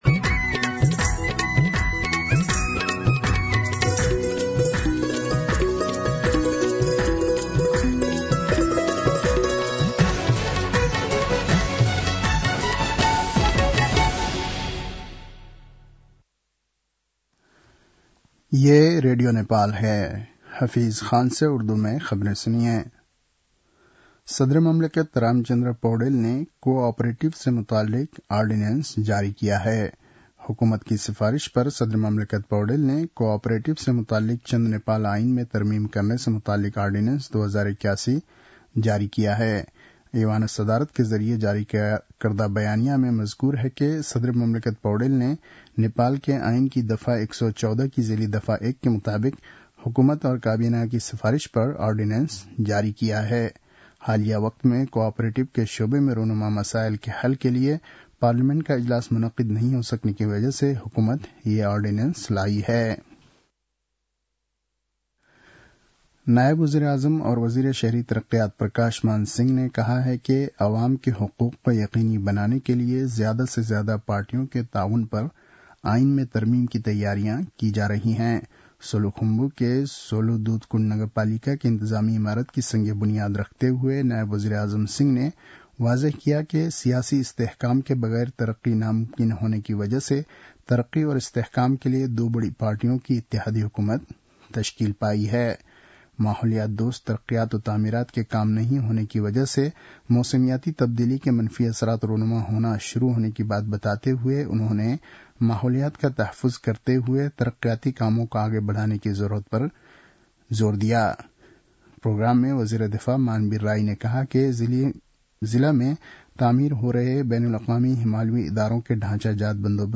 उर्दु भाषामा समाचार : १५ पुष , २०८१
Urdu-News-9-14.mp3